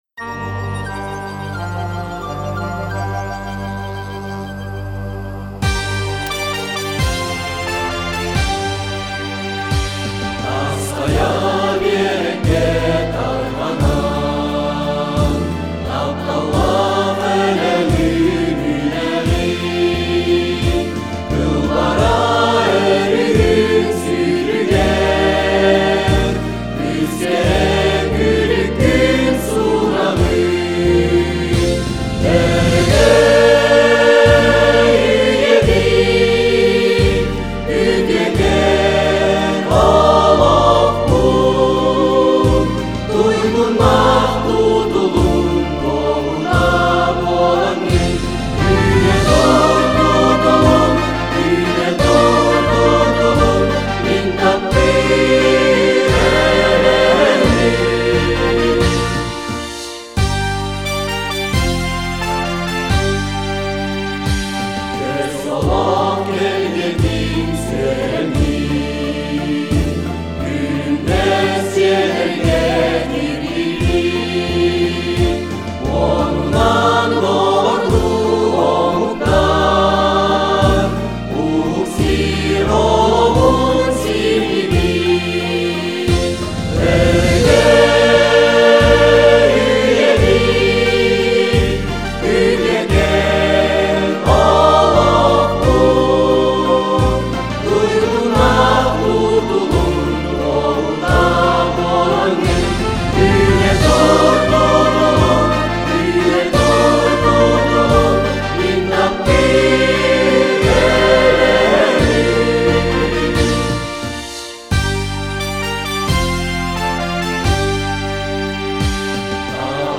aczkij-hor-gimn-olenyokskogo-ulusa-kissvk.com_.mp3